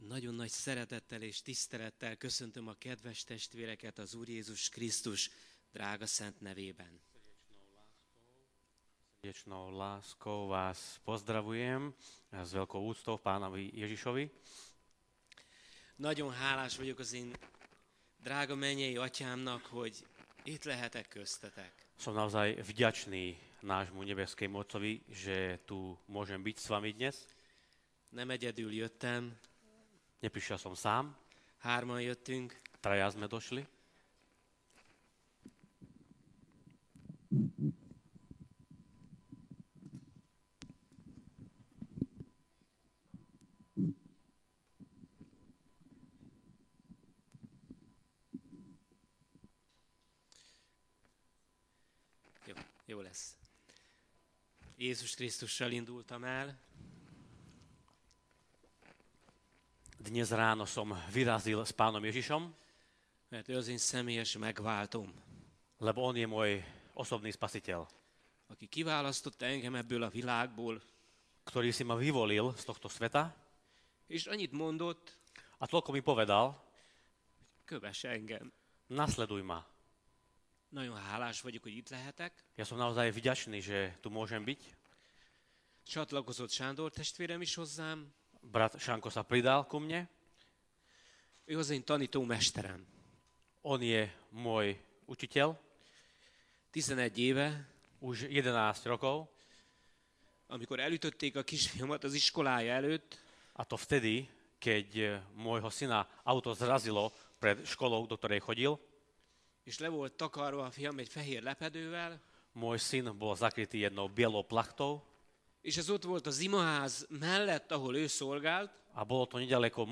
Vypočujte si kázne z našich Bohoslužieb